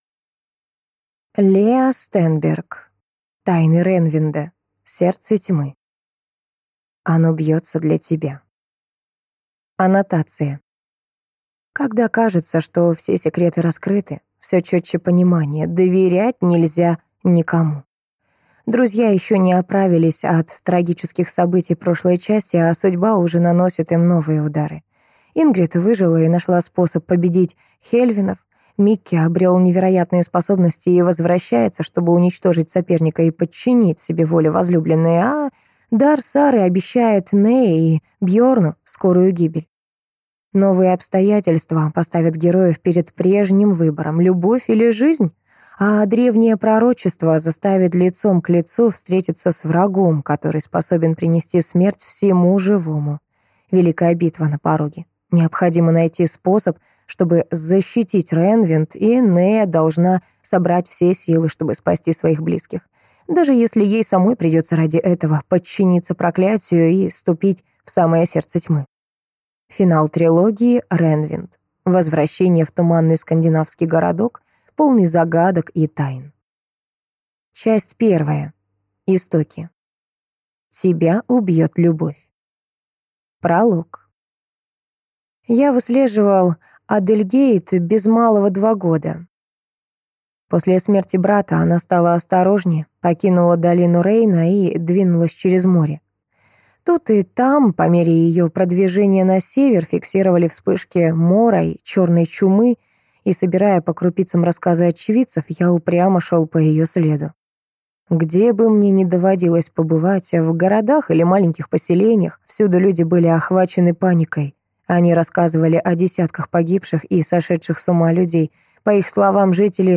Аудиокнига Тайны Реннвинда. Сердце тьмы | Библиотека аудиокниг